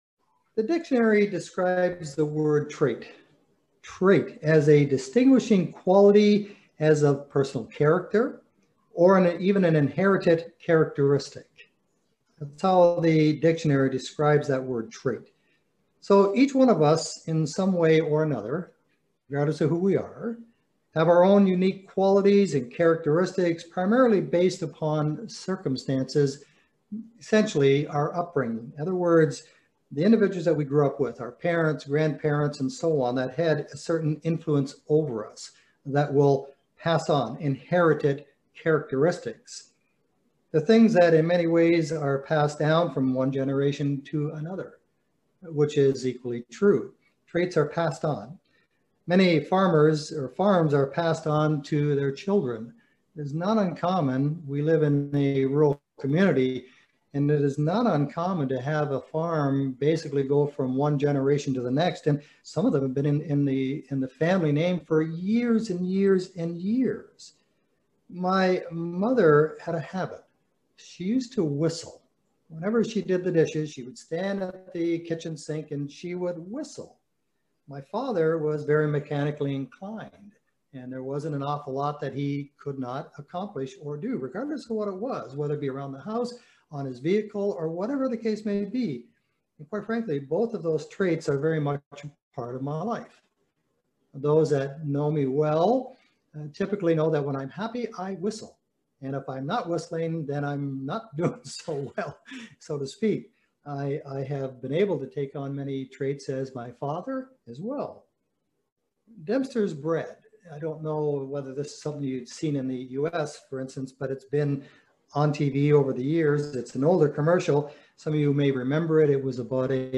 Join us for this extraordinary video sermon on the subject of God is our builder.